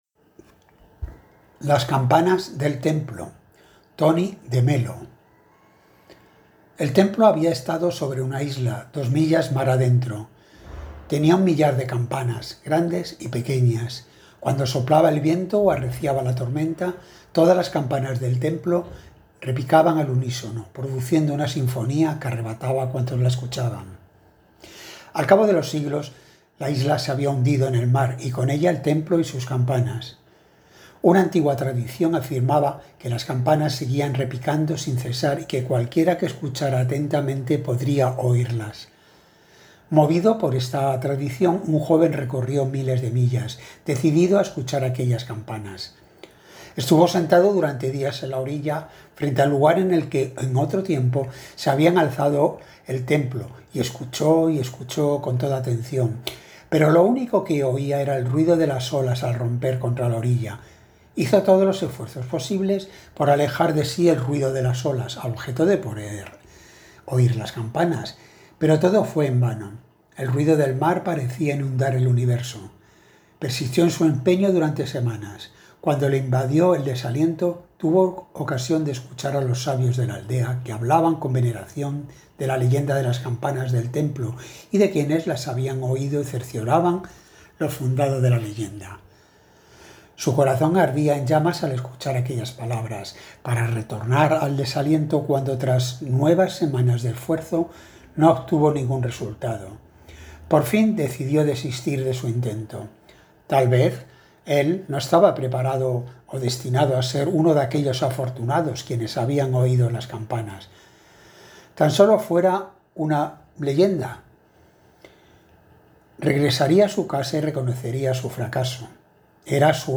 Modalidad cuento
con la lectura de “Las campanas del templo” (Anthony de Mello).